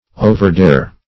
overdare - definition of overdare - synonyms, pronunciation, spelling from Free Dictionary
\O`ver*dare"\